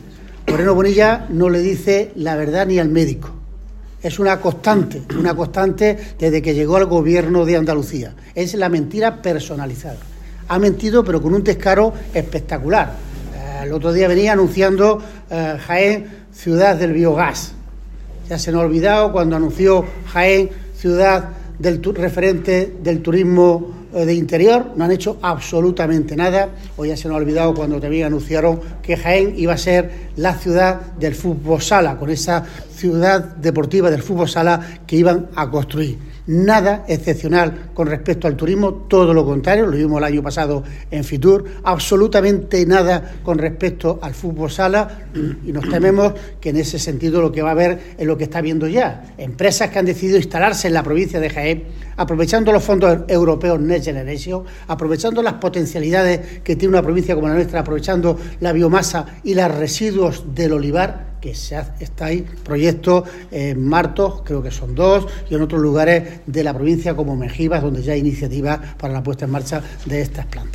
Declaraciones de Reyes y Millán tras reunión del Grupo Parlamentario y del Grupo Municipal
Cortes de sonido
Francisco-Reyes-reunion-capital.mp3